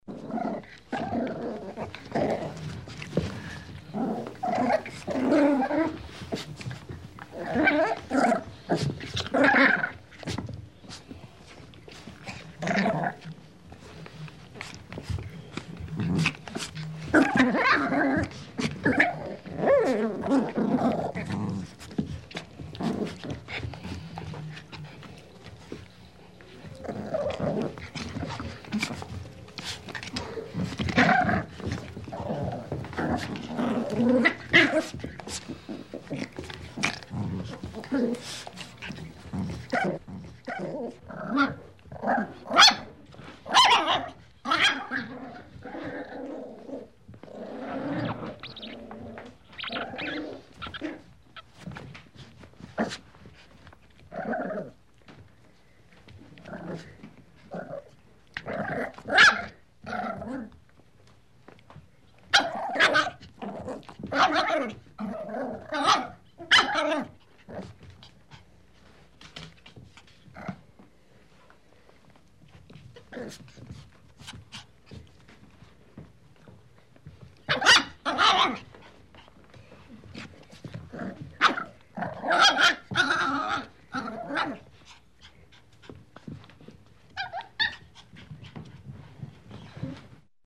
Звуки щенков
Звук игривого щенка рычащего